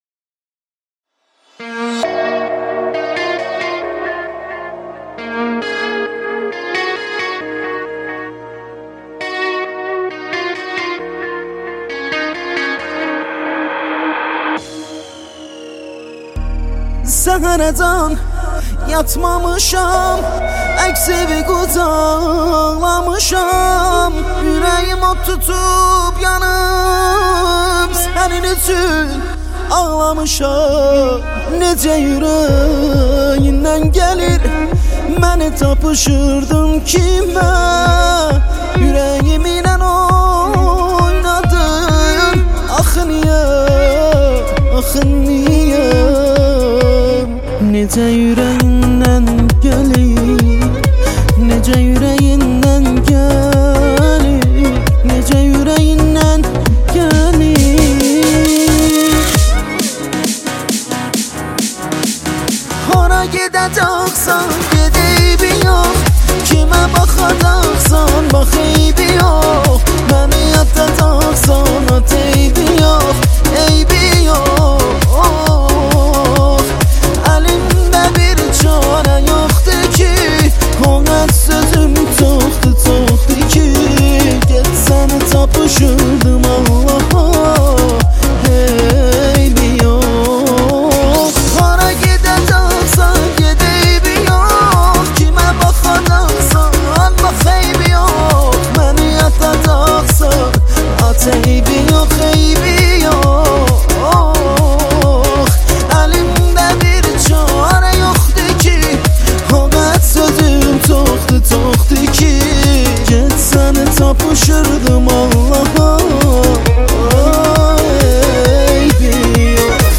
ویولن